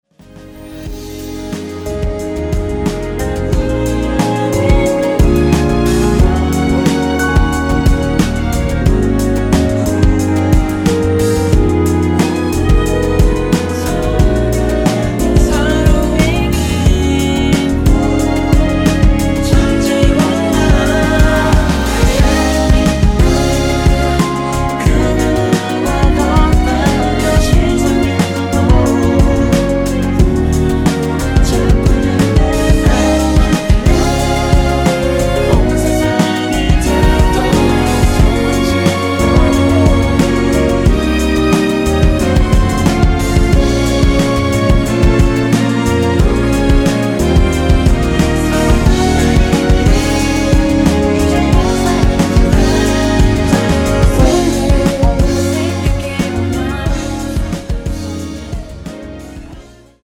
원키에서(-2)내린 멜로디와 코러스 포함된 MR입니다.(미리듣기 확인)
Db
앞부분30초, 뒷부분30초씩 편집해서 올려 드리고 있습니다.